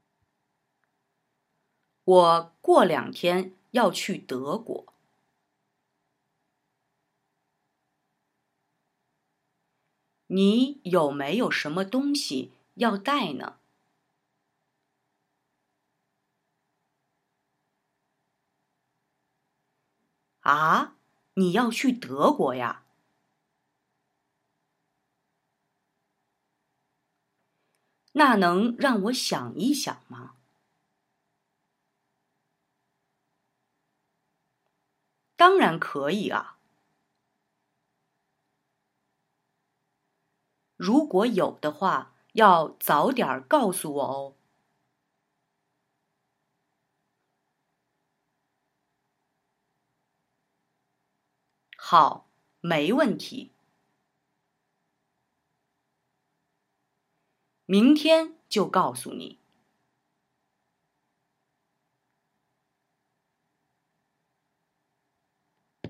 Lasst unser Übungsaudio laufen und wiederholt in den Leerphasen einzeln die vorgesprochenen Sätze.
Versucht möglichst ein Gefühl für den Satzrhythmus unserer Sprecherin zu entwickeln.
Übung 1: Hören und Satz für Satz nachsprechen